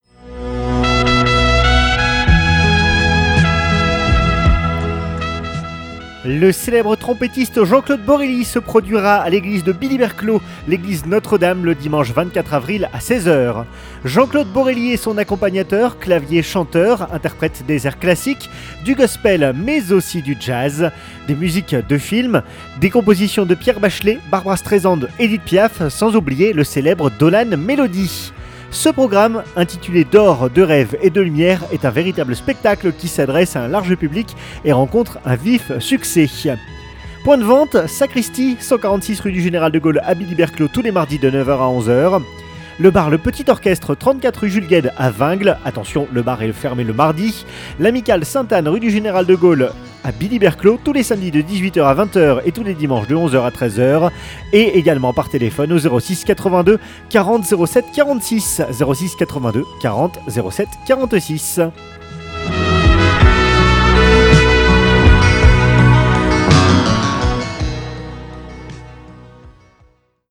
Jean-Claude Borelly en interview!
Avant ce concert, il nous a accordé une interview, à retrouver sur les ondes de Radio Plus: